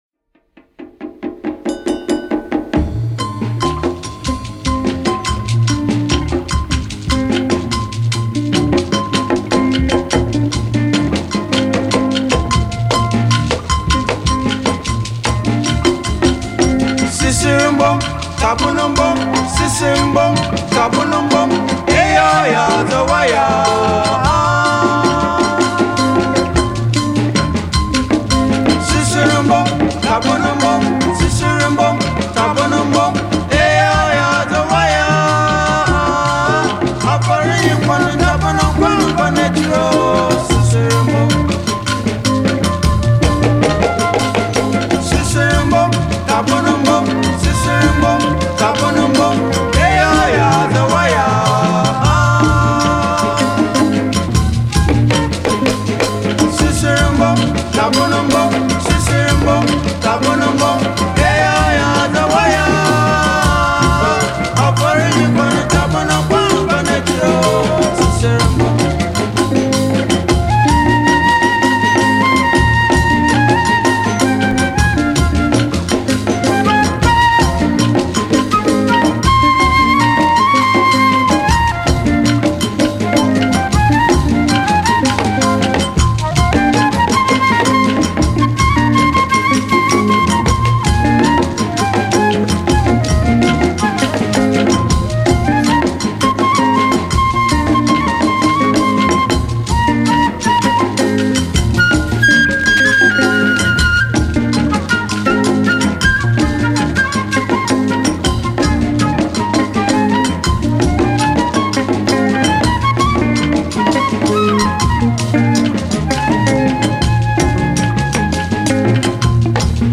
Sun-and-fun music which lights up gray winter days 🙂